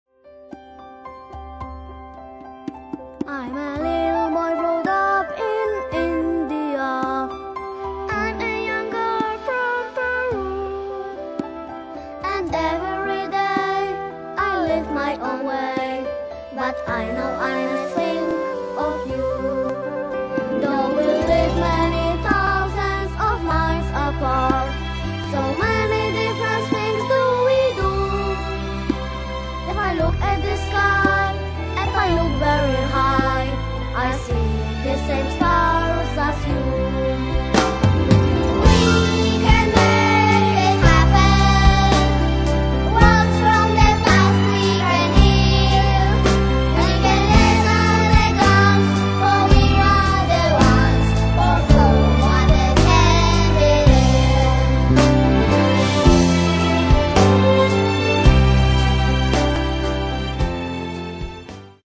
W międzyczasie poznańskie Radio Merkury udostępniło im na trzy dni studio nagraniowe.